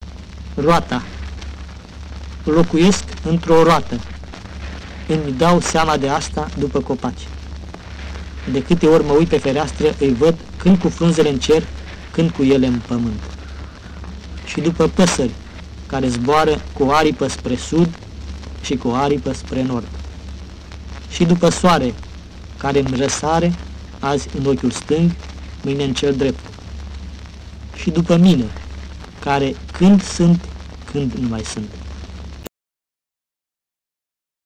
Din volumul "Poeme" (1965) autorul Marin Sorescu citeşte versuri.